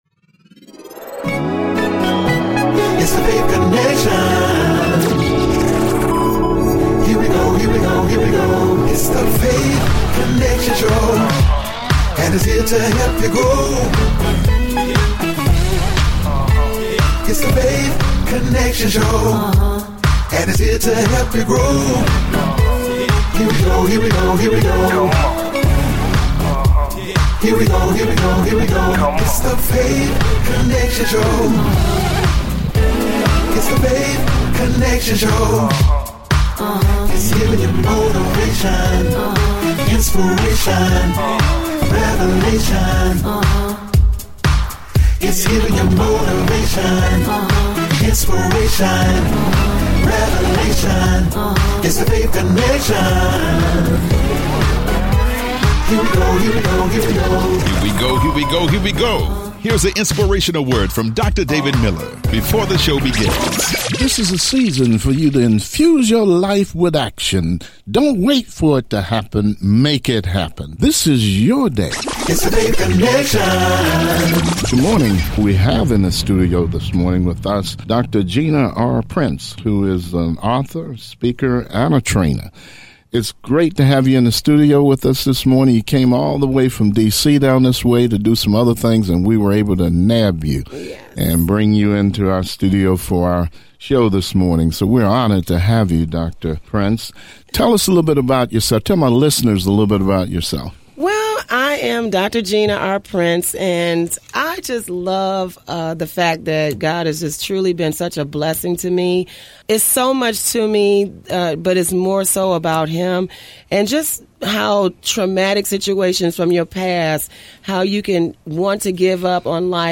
Weekly talk show in which we interview people that have gone through tremendous struggles, have overcome and been able to succeed.